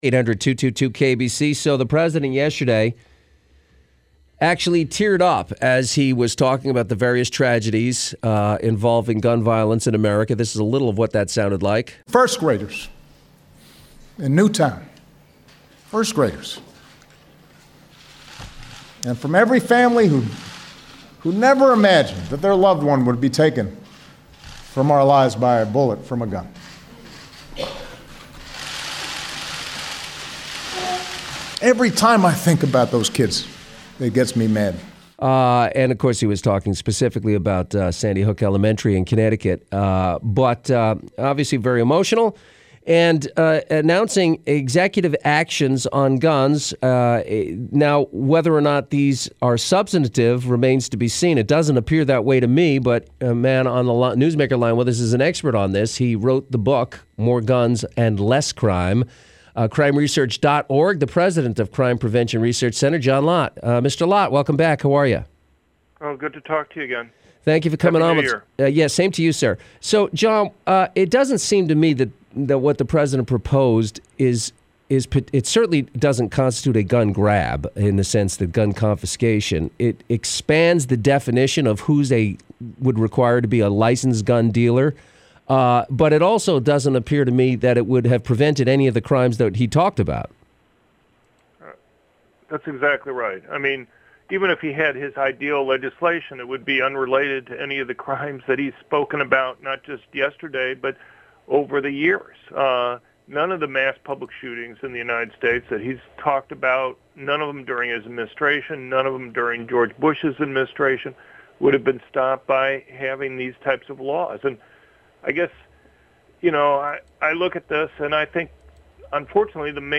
CPRC on Los Angeles’ big talk radio station KABC to discuss Obama’s new gun regulations
media appearance